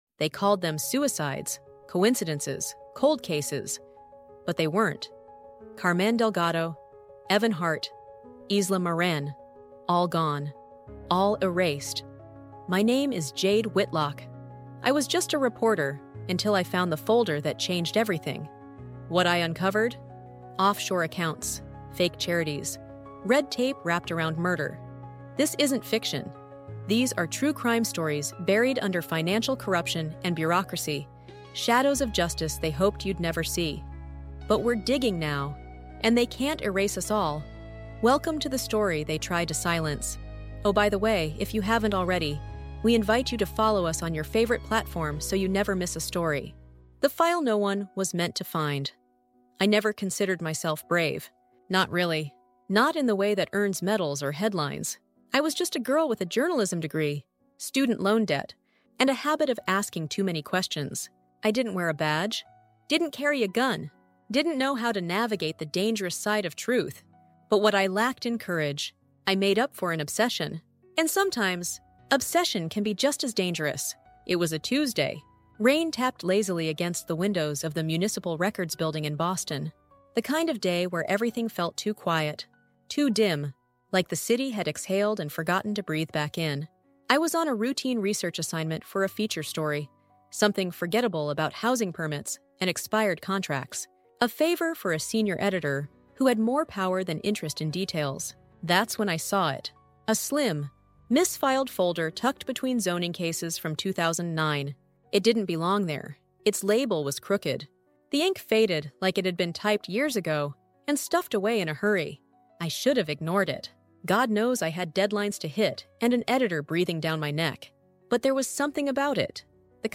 Exploring Unsolved Cases That Leave You Speechless is a raw, first-person true crime thriller that tears through layers of red tape, financial corruption, and conspiracy. Told through the haunting voice of investigative journalist
this cinematic audiobook dives into murder tales masked as suicides